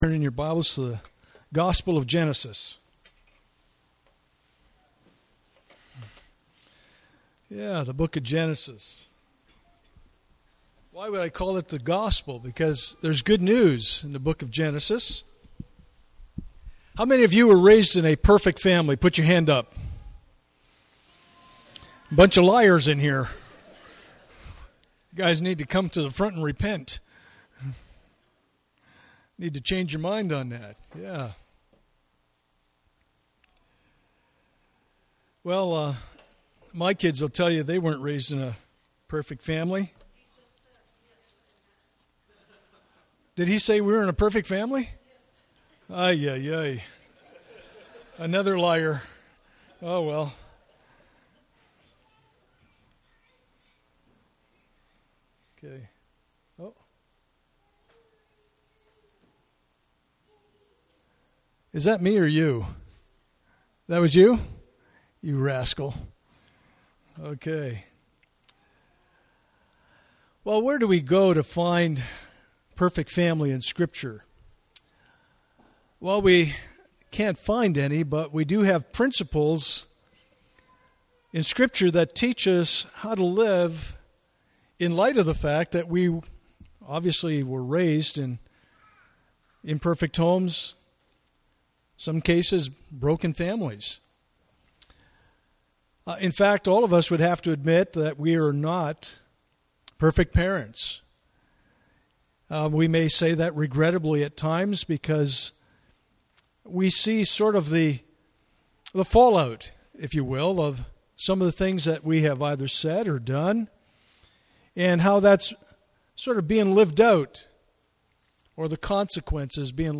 Passage: Genesis 37:1-11 Service Type: Sunday Morning